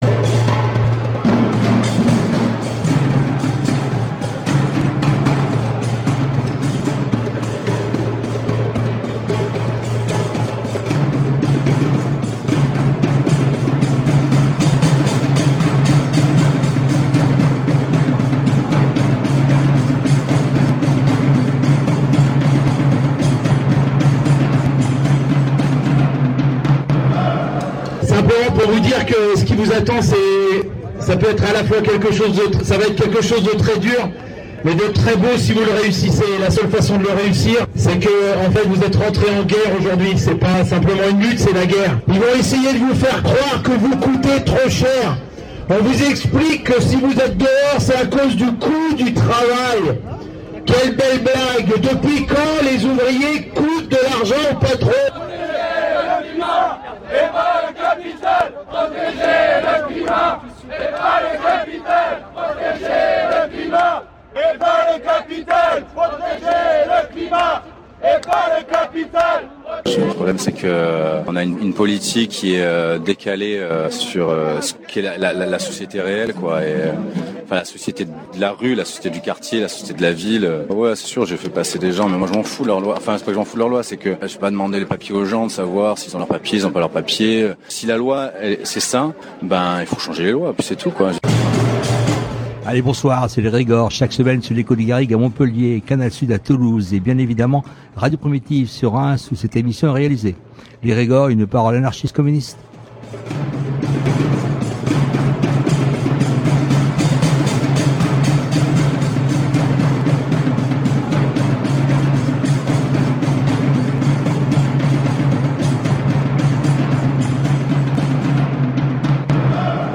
Dans l’émission de ce jour, nous entendrons l’entretien réalisé sur le piquet de grève début mars avec une salariée, qui nous parlera des motifs de cette grève et nous poursuivrons avec la soirée du 11 mars et la signature victorieuse de cette grève et les diverses prises de parole et interview réalisé ce même jour. classé dans : société Derniers podcasts Découvrez le Conservatoire à rayonnement régional de Reims autrement !